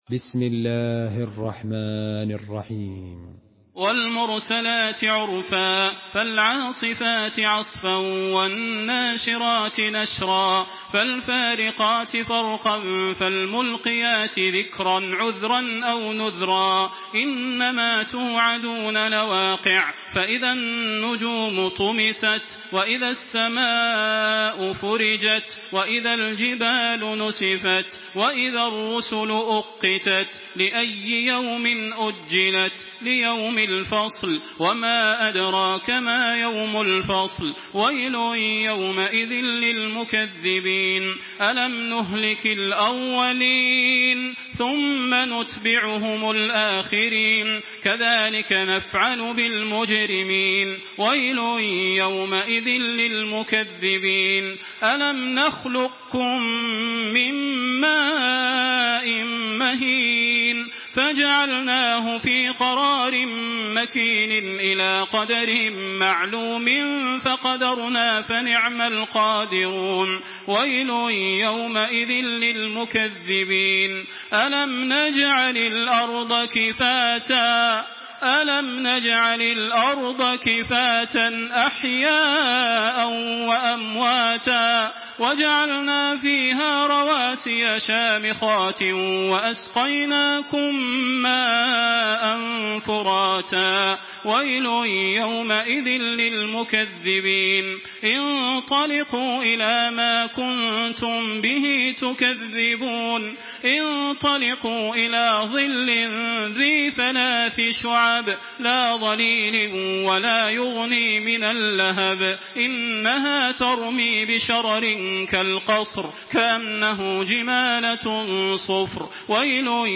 سورة المرسلات MP3 بصوت تراويح الحرم المكي 1426 برواية حفص
مرتل